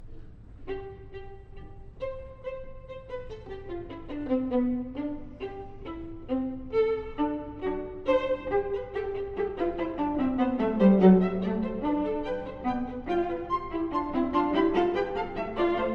↑古い録音のため聴きづらいかもしれません！（以下同様）
曲調はとても軽い。
パブリックドメインで聞ける音源は、とてものびのびとしています。
また、この楽章は「フーガ」形式です。